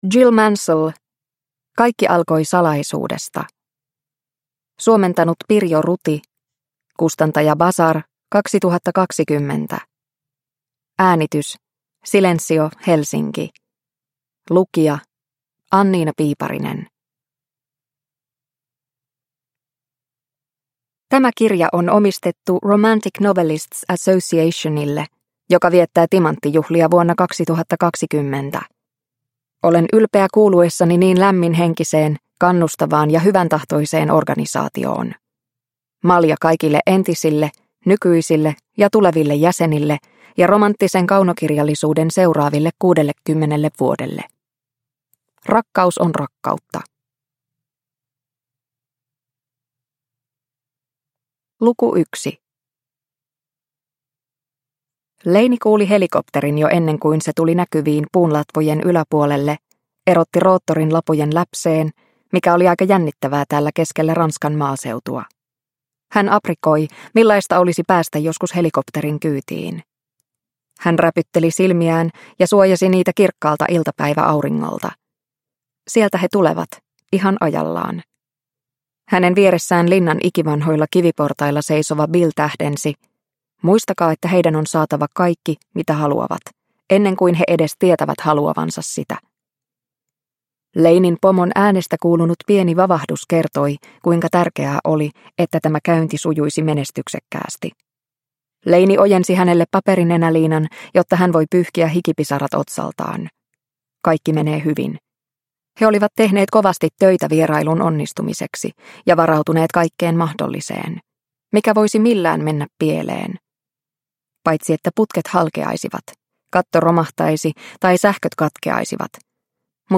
Kaikki alkoi salaisuudesta – Ljudbok – Laddas ner